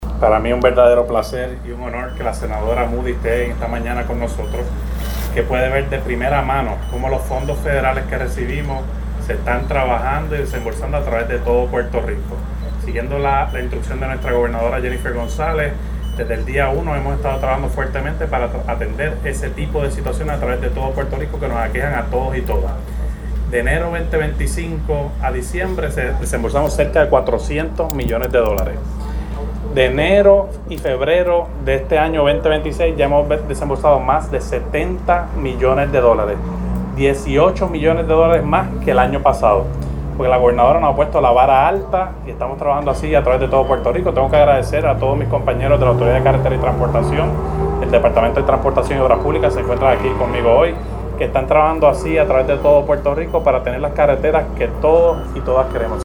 Los trabajos de reconstrucción serán en el tramo de la PR-181 entre los kilómetros 38.6 y 55.0, que atraviesa Trujillo Alto, Gurabo y San Lorenzo y conllevarán escarificado de asfalto existente, repavimentación, marcado termoplástico, instalación de ojos de gato, construcción de cunetones, instalación de “guard rails”, control de erosión y la preservación para el puente #2652 sobre el Río Gurabo, explico el secretario del Departamento de Transportación y Obras Públicas (DTOP) y director ejecutivo de la Autoridad de Carreteras y Transportación (ACT), Edwin González